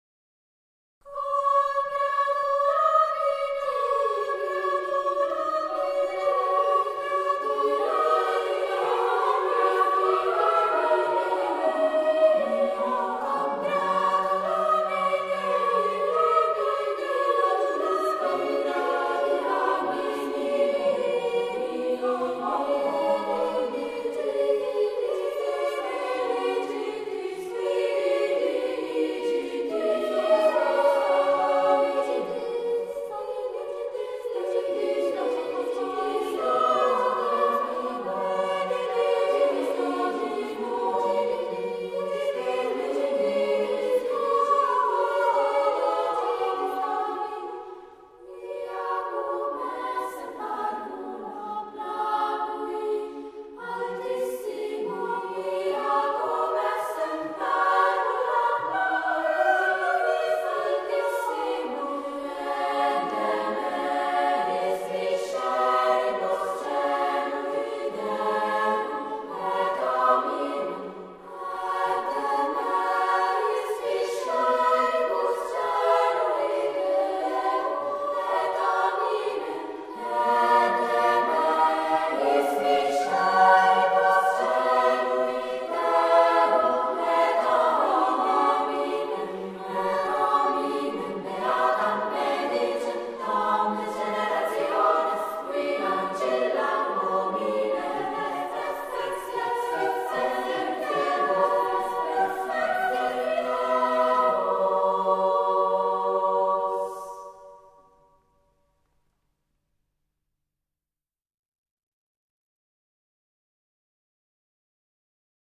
Registrato nel 2003 negli Studi RSI a Lugano.